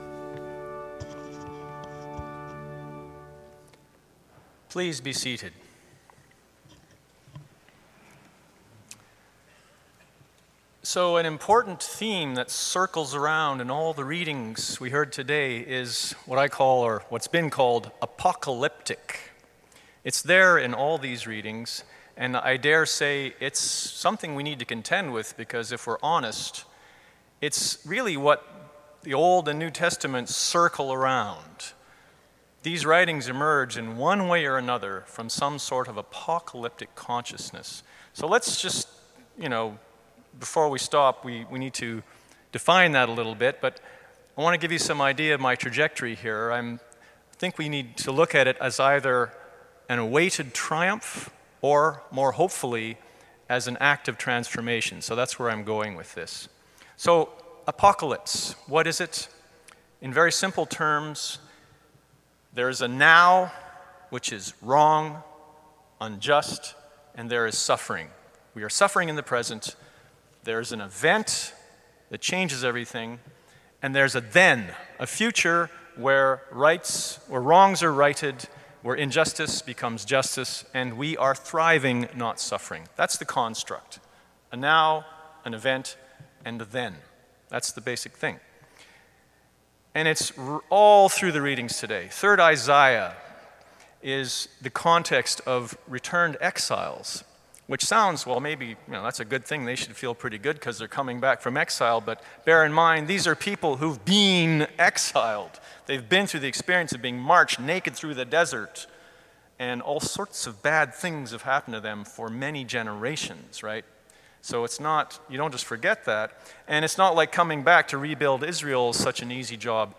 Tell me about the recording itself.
Sermon: 9.15 a.m. service